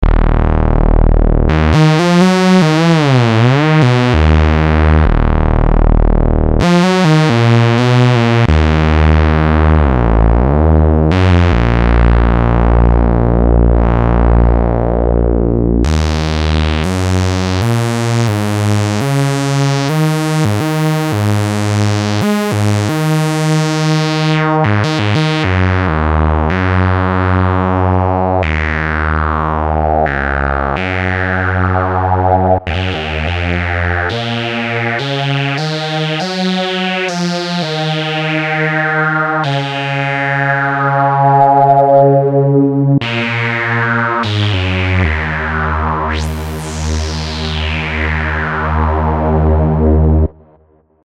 Но часто думаю о его характере звука, что ни рыба ни мясо.
И атака у него реально быстрая. Вложения ASM Hydra JS Bass-Lead.mp3 ASM Hydra JS Bass-Lead.mp3 2 MB · Просмотры: 1.440